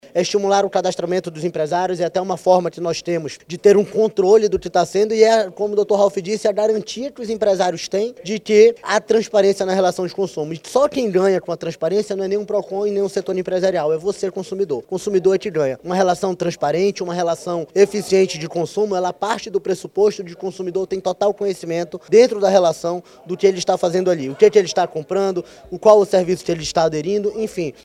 A iniciativa tem por objetivo contemplar os estabelecimentos que respeitam o Código de Defesa do Consumidor, como explica o diretor-presidente do Procon-AM, Jalil Fraxe.
SONORA01_JALIL-FRAXE.mp3